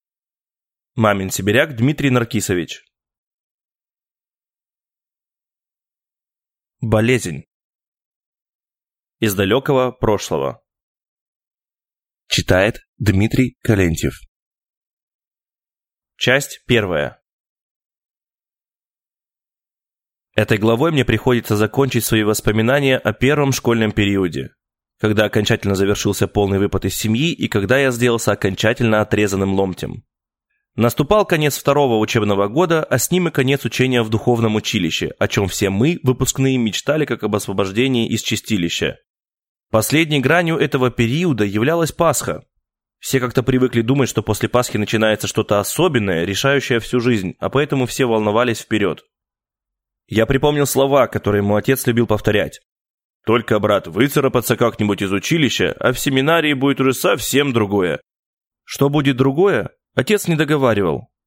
Аудиокнига Болезнь | Библиотека аудиокниг